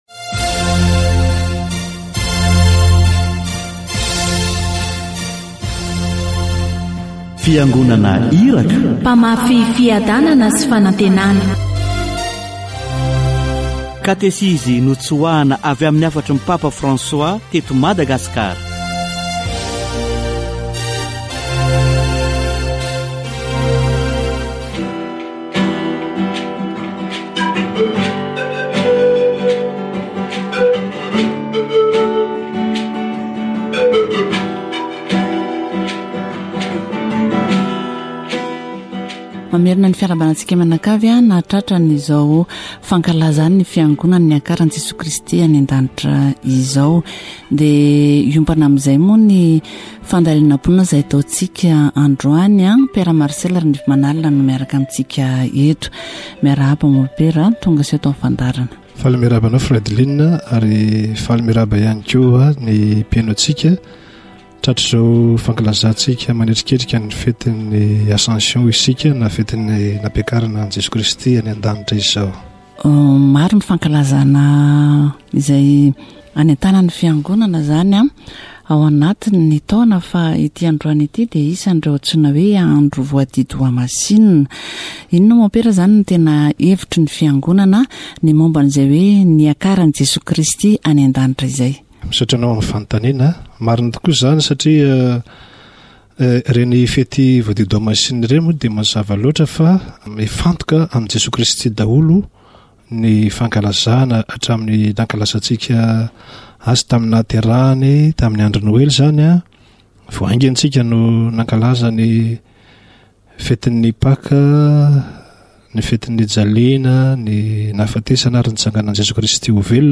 Misy lafiny roa ny mistery ankalazaina : voalohany dia Izy Jesoa lasa nody any amin'ny Rainy izay niaviany ka tsy hitantsika maso intsony ; ny faharoa dia  mipetraka eo an-kavanan'Andriamanitra Rainy izy ary maneho ny voninahiny izay efa nananany. Katesizy momba ny niakaran'i Jesoa any an-danitra